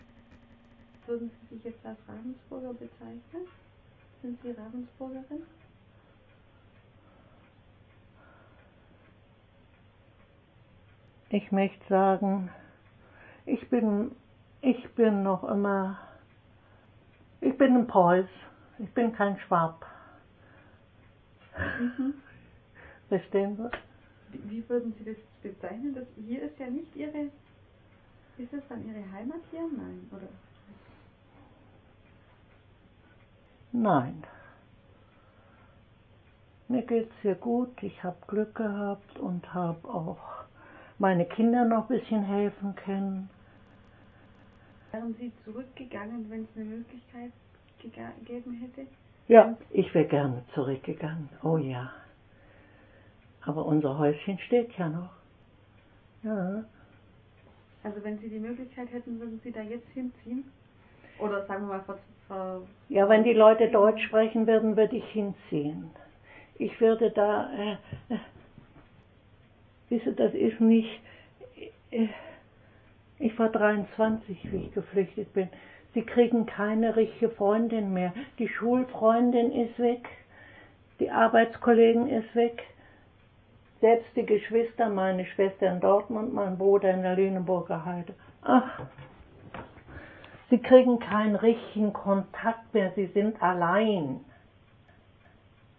Audio 1: Interviewausschnitt